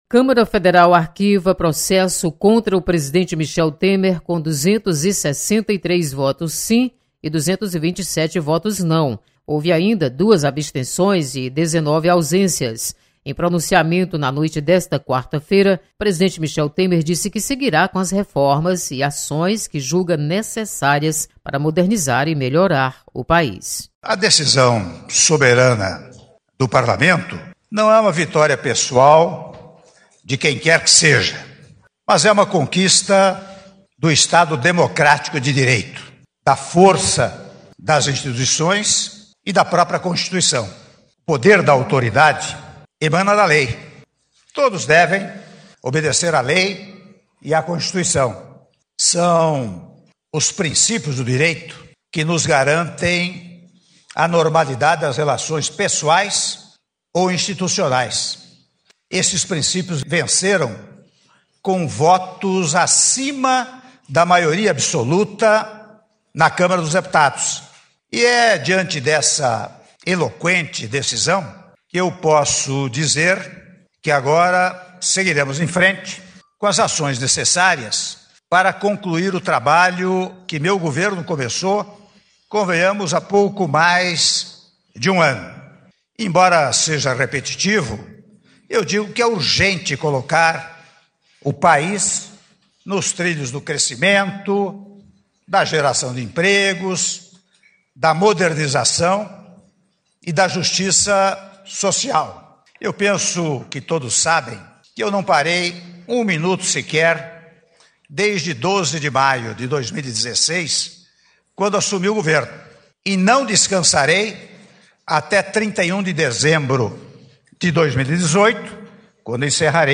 Deputado Fernando Hugo  comenta sobre arquivamento do processo contra o presidente  Michel Temer.